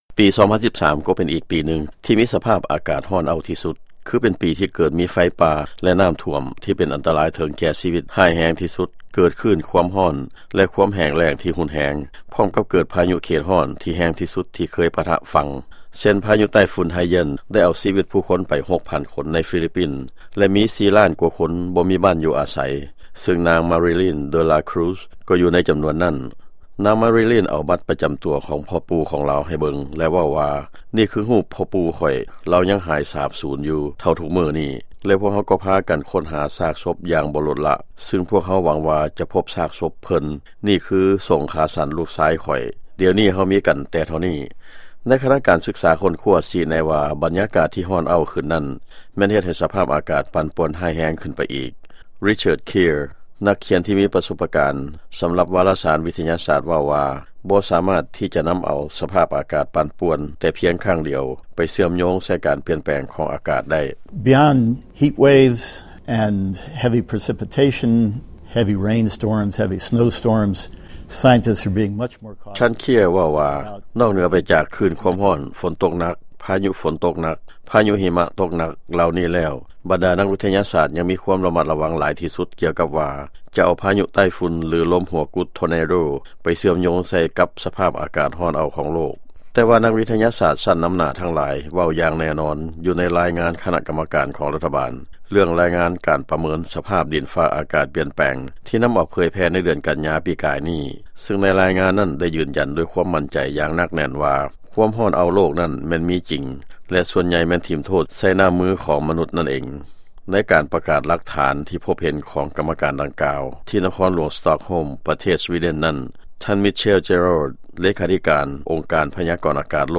ຟັງລາຍງານ 2013 ເປັນປີ ທີ່ມີສະພາບອາກາດຮ້າຍແຮງ ທີ່ສຸດ